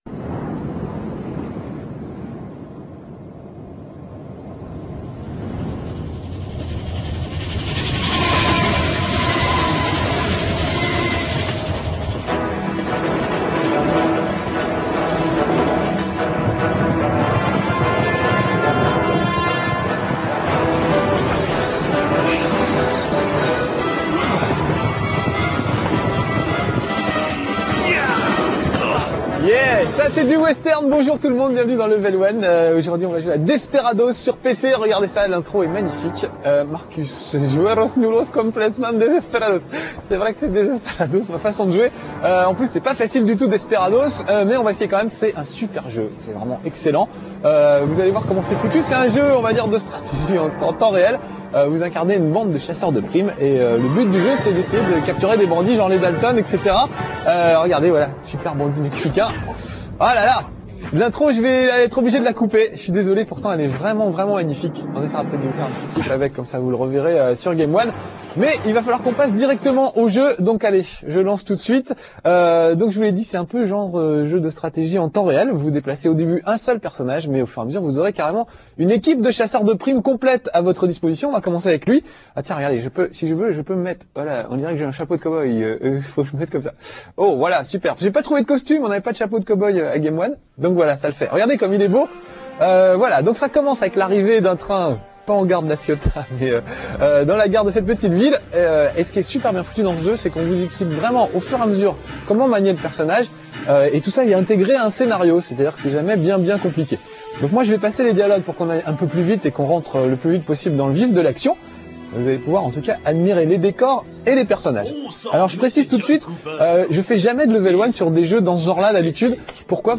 La qualité de la vidéo en streaming Real Multimedia est volontairement dégradée afin qu'elle soit rapidement téléchargée et affichée.